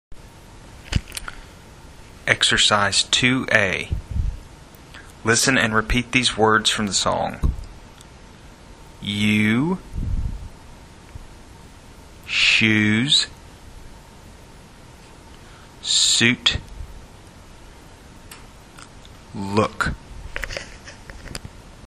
Ressource utilisée: piste son enregistrée par l’assistant sur le baladeur et CD.